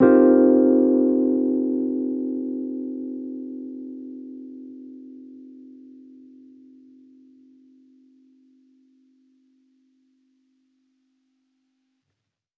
Index of /musicradar/jazz-keys-samples/Chord Hits/Electric Piano 1
JK_ElPiano1_Chord-Cm11.wav